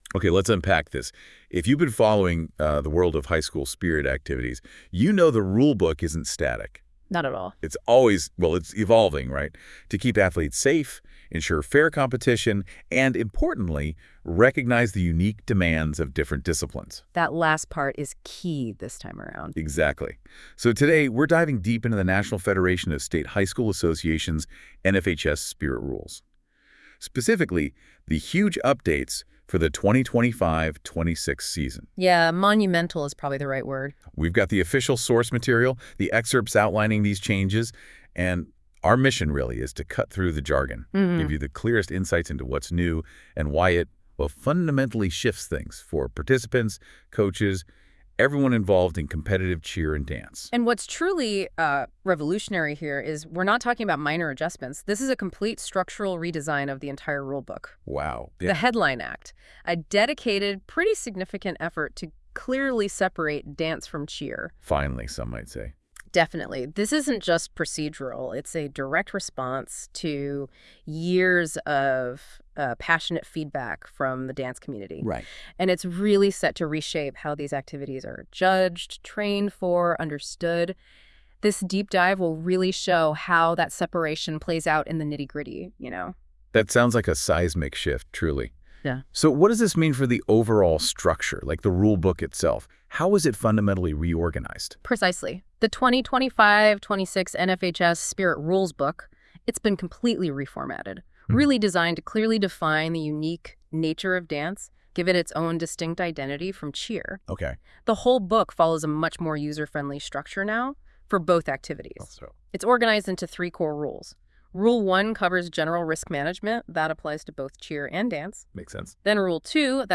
Listen to a deep dive conversation into this year's NFHS Spirit rule changes Listen Now